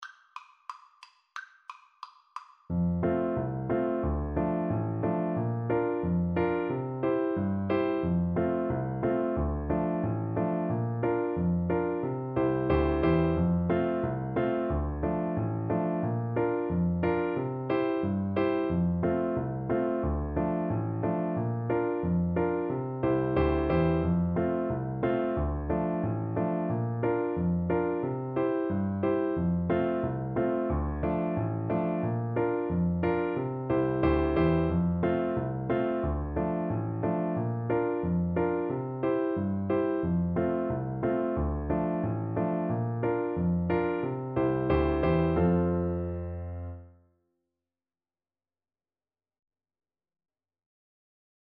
Very fast = c.180